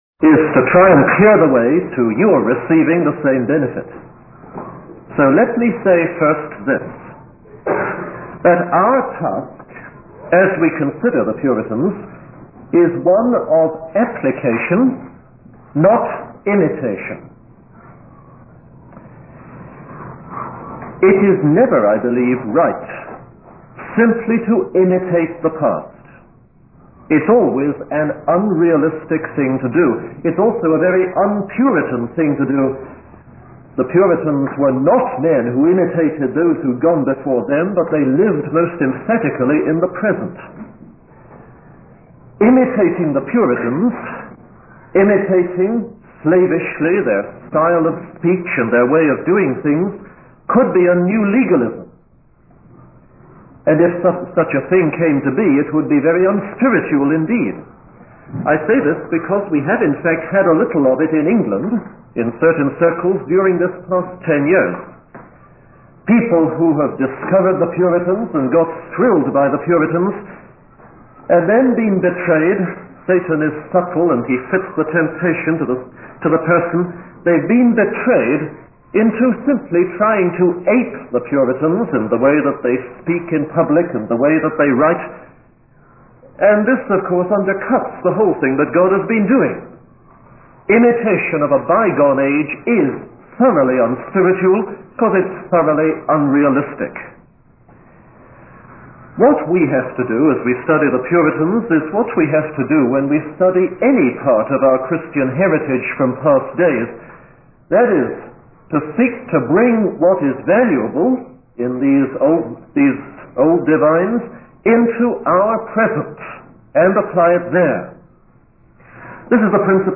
In this sermon, the preacher emphasizes four important reminders for believers regarding their sanctification.